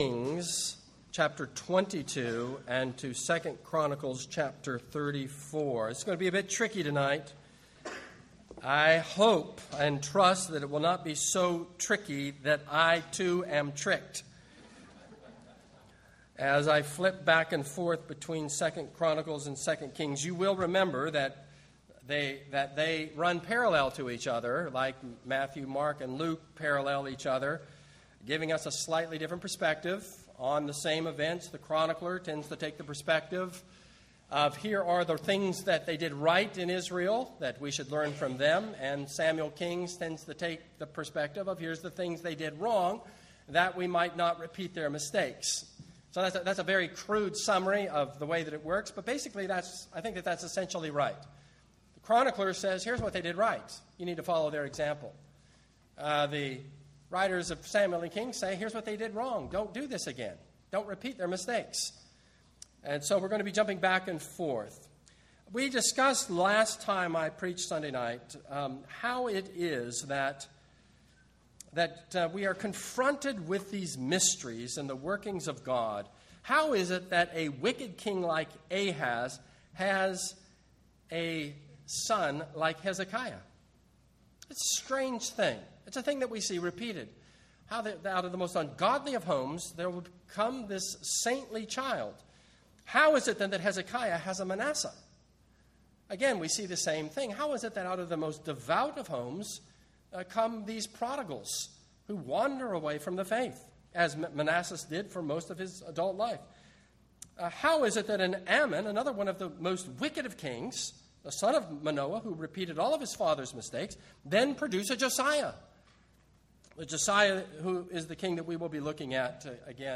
This is a sermon on 2 Kings 22-23:30.